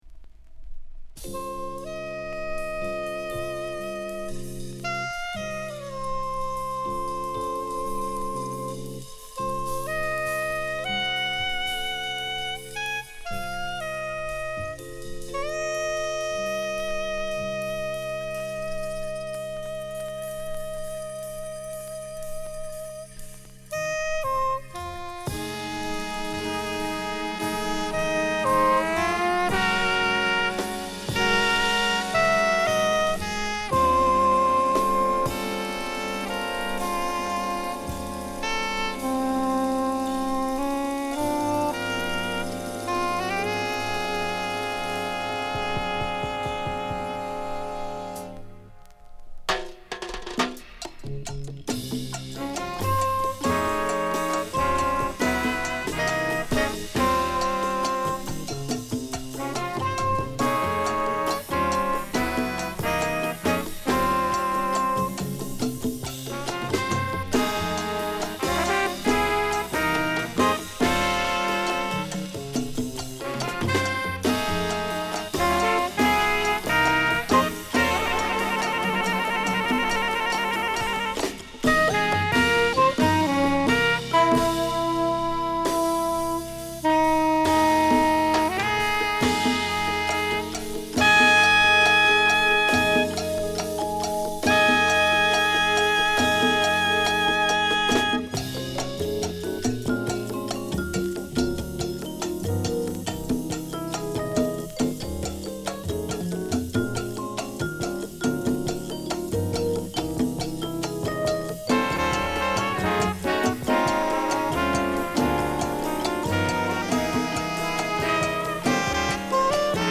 ゴージャズなホーン隊をfeat.したラテンジャズA1
タイトにパーカッションがキープするB2
ラテンジャズ〜レアグルーヴの強力トラックを満載！